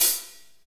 HAT P B S0BR.wav